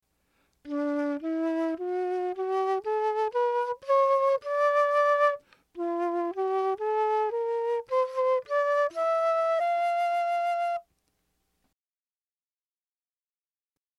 Flûte baroque: Ecouter!
flute baroque.mp3